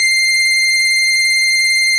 snes_synth_084.wav